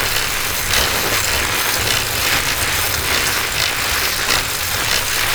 motor_wheel.wav